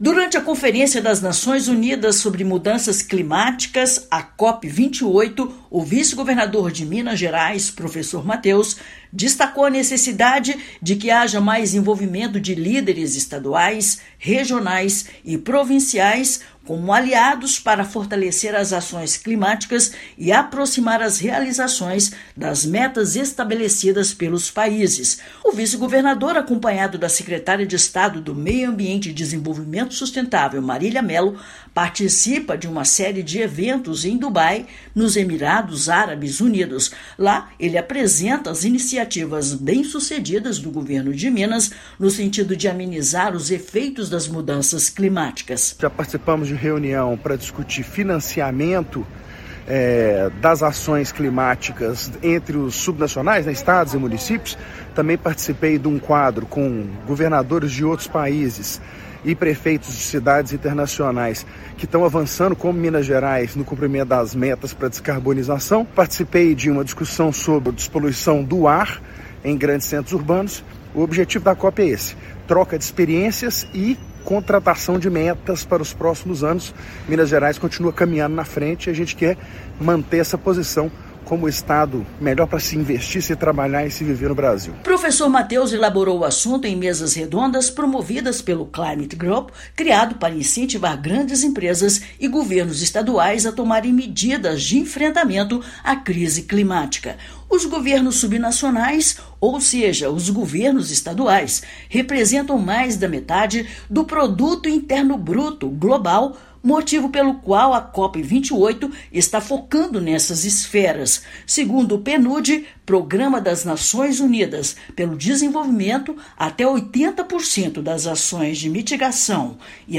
VICE-GOVERNADOR_NA_COP_28_-1.mp3